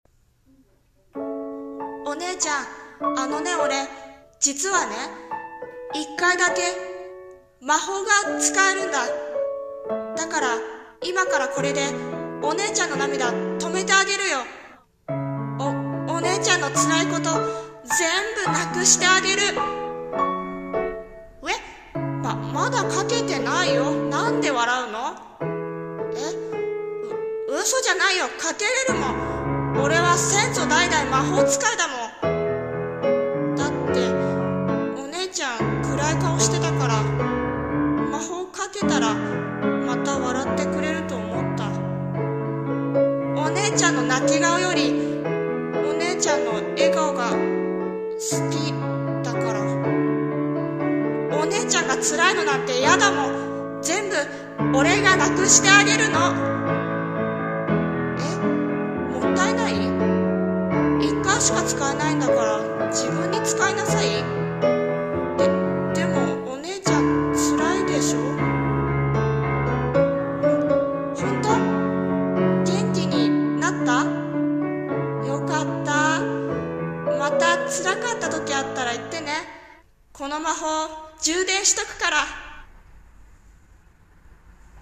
さんの投稿した曲一覧 を表示 〖 一人声劇 〗可愛い嘘。